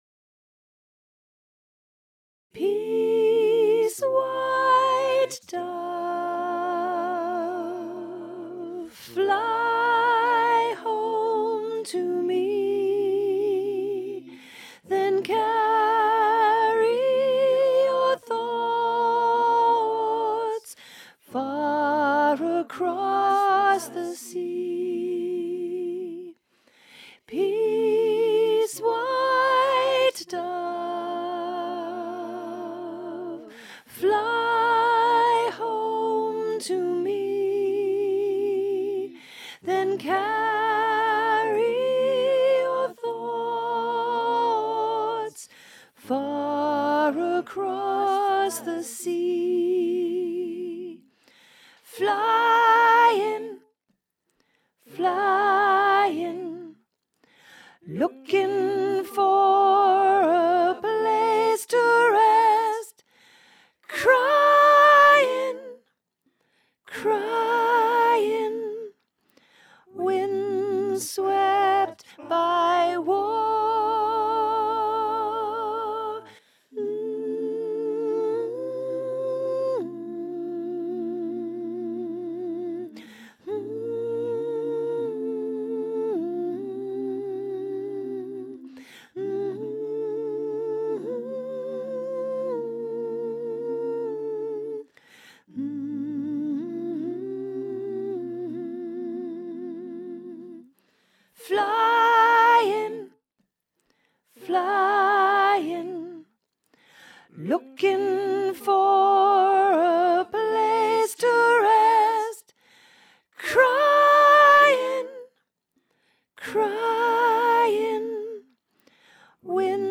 Vox Populi Choir is a community choir based in Carlton and open to all comers.
Peace_white_dove_Soprano.mp3